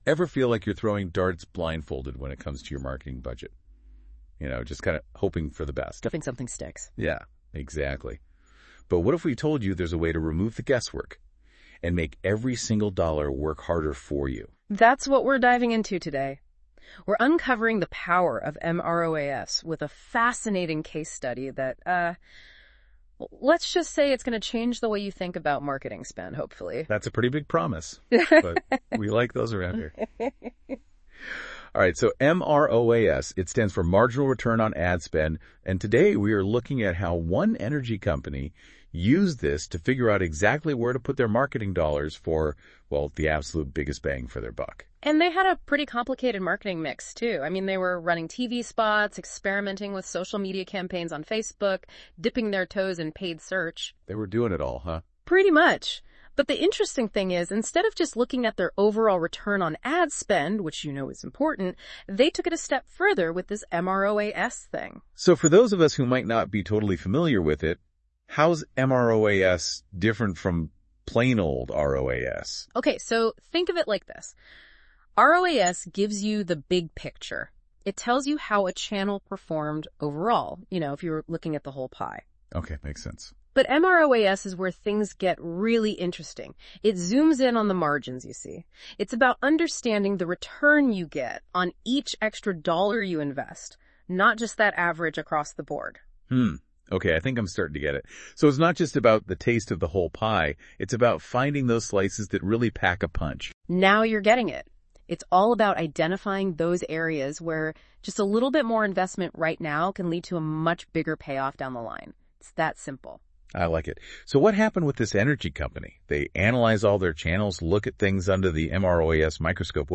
Note : This Podcast is generated through Notebook LM. Curated and Verified by Aryma Labs for Accuracy.